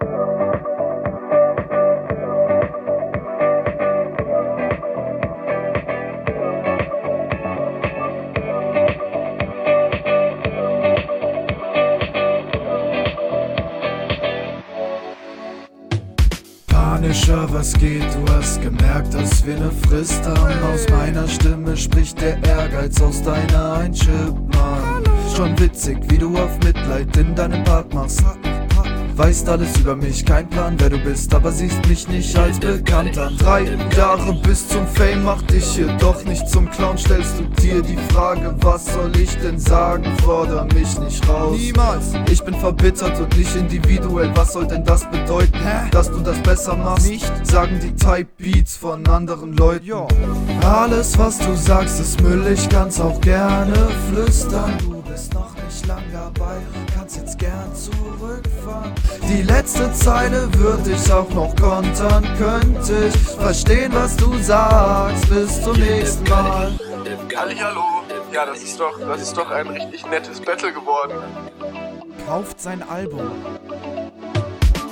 A u t o t u n e bitte, der Anfang ist schief wie fick.